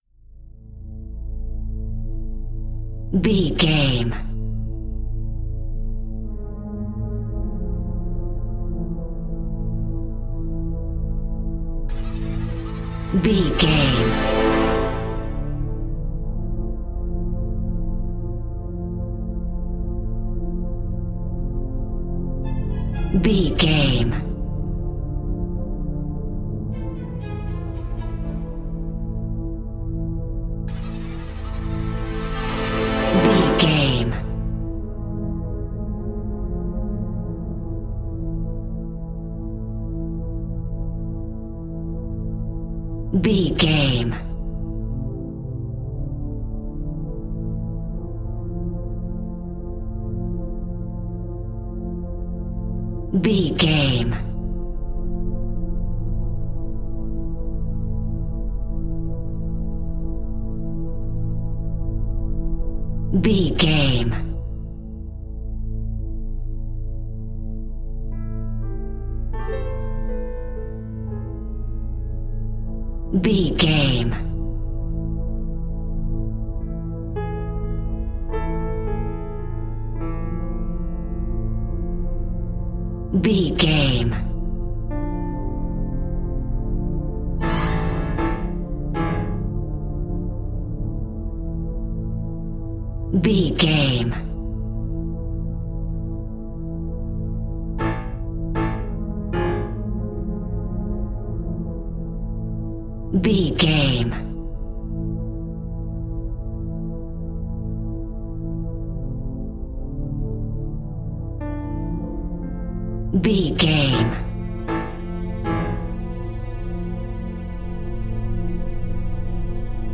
In-crescendo
Thriller
Atonal
ominous
dark
suspense
eerie
strings
piano
synthesiser
Synth Pads
atmospheres